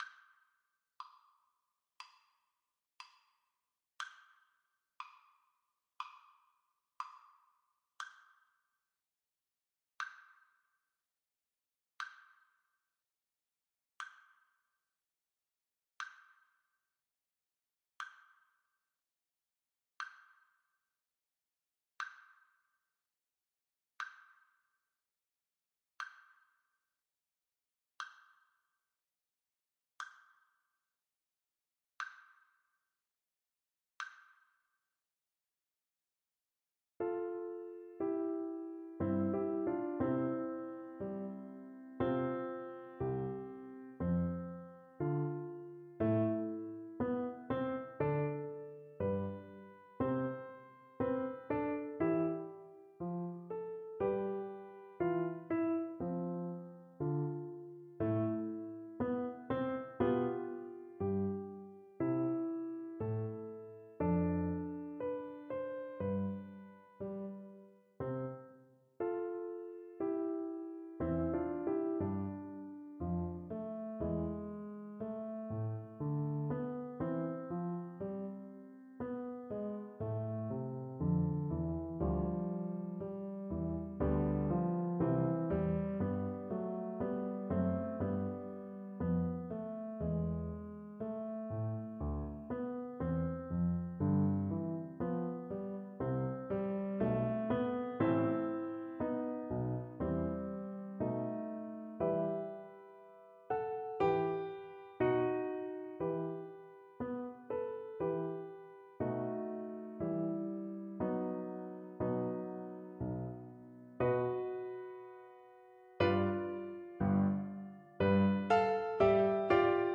Trombone
4/4 (View more 4/4 Music)
Andante
Arrangement for Trombone and Piano
C major (Sounding Pitch) (View more C major Music for Trombone )
Classical (View more Classical Trombone Music)